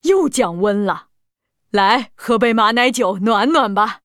文件 文件历史 文件用途 全域文件用途 Balena_amb_03.ogg （Ogg Vorbis声音文件，长度4.0秒，101 kbps，文件大小：50 KB） 源地址:游戏语音 文件历史 点击某个日期/时间查看对应时刻的文件。